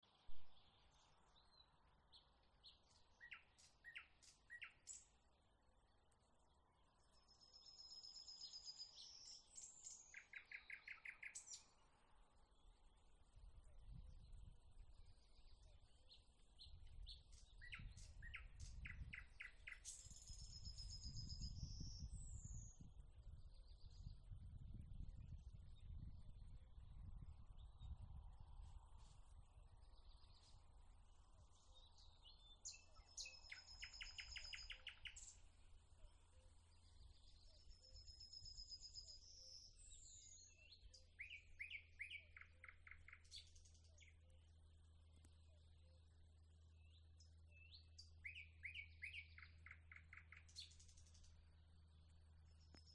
Thrush Nightingale, Luscinia luscinia
Administratīvā teritorijaViļakas novads
StatusSinging male in breeding season